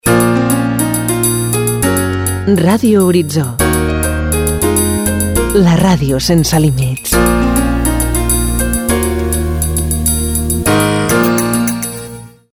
Dos indicatius de la ràdio "sense límits"